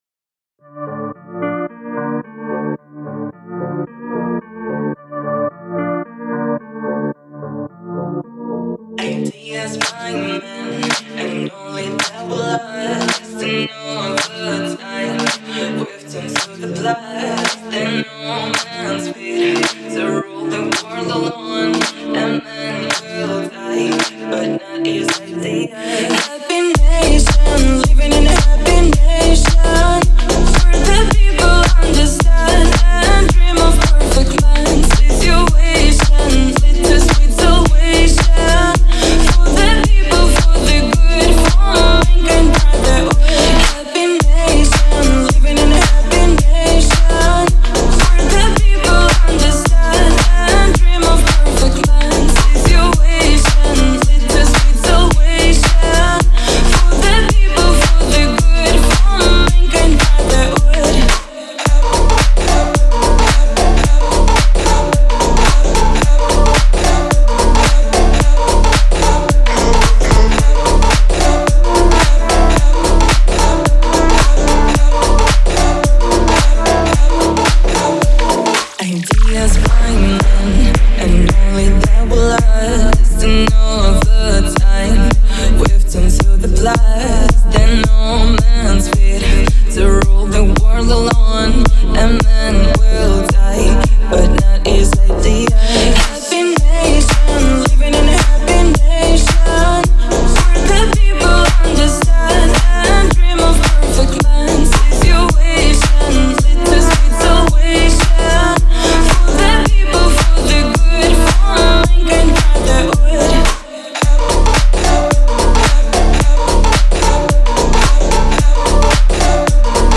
а динамичные биты заставляют двигаться.